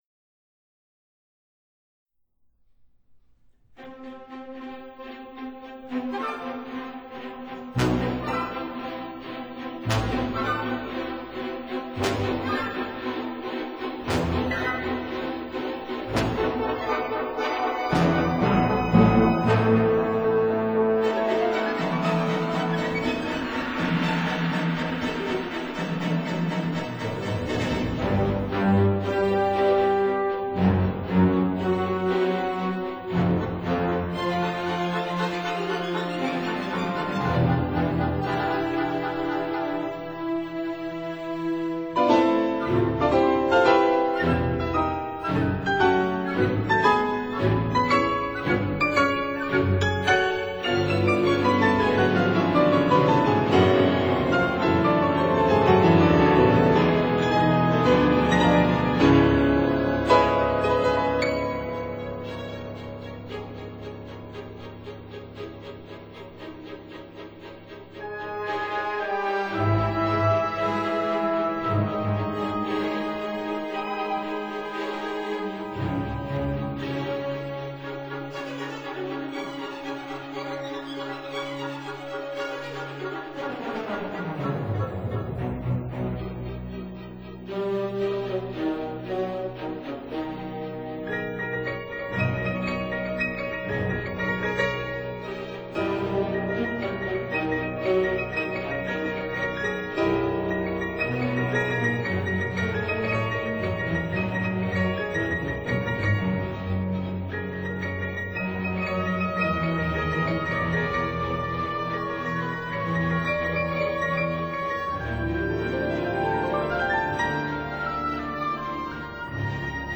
for piano and orchestra
for winds, violin and cello